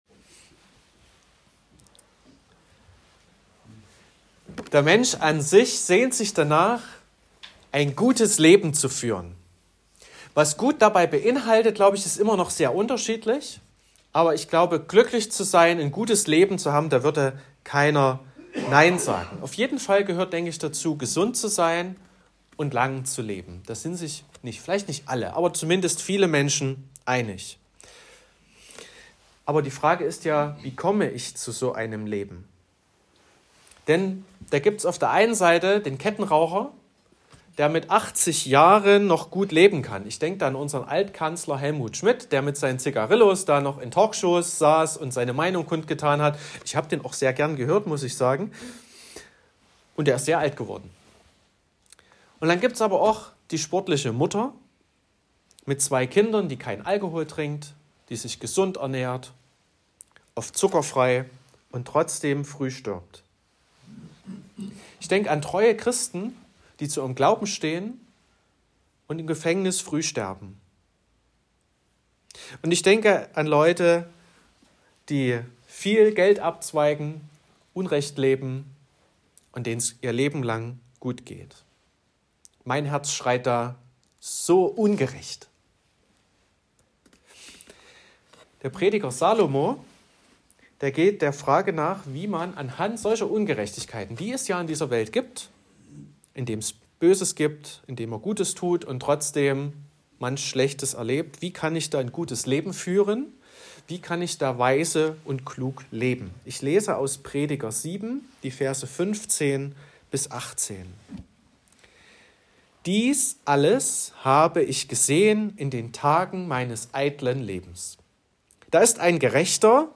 16.02.2025 – Gottesdienst
Predigt (Audio): 2025-02-16_Ein_glueckliches_Leben.m4a (9,0 MB)